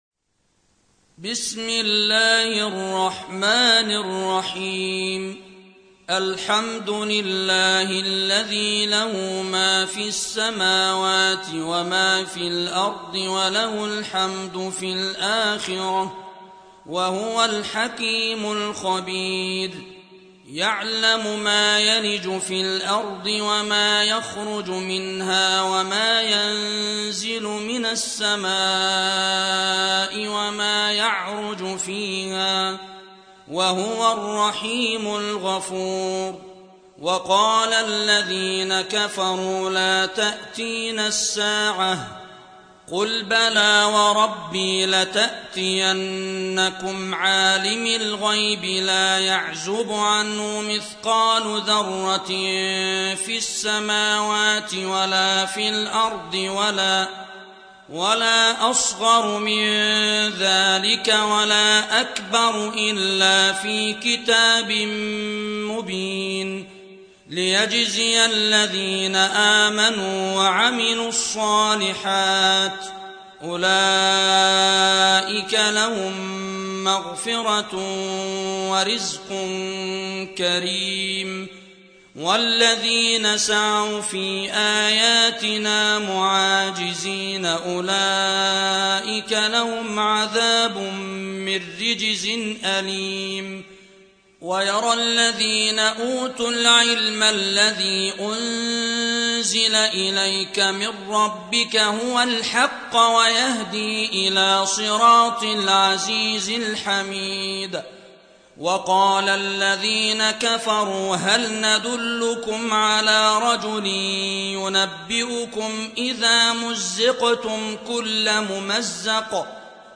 القارئ